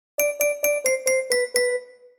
Звук ксилофона на сообщение СКАЧАТЬ РИНГТОН